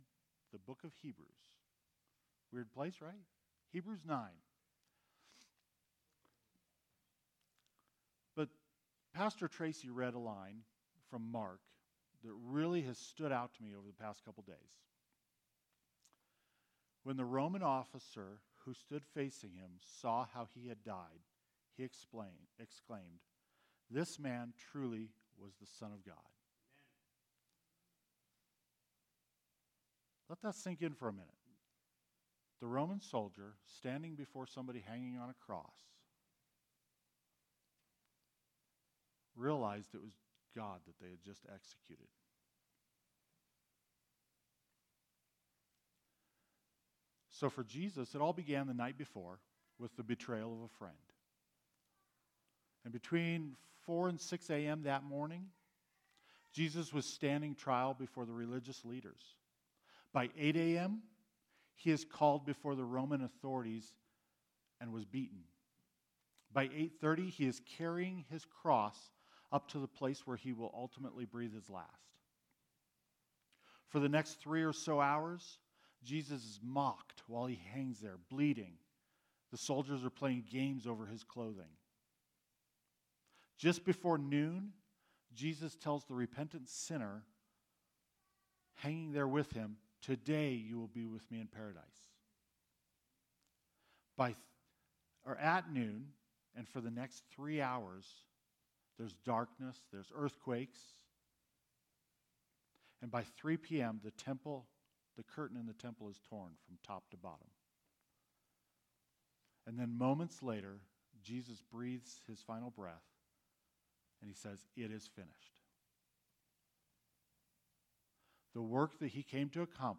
Good Friday Service for 2021 year...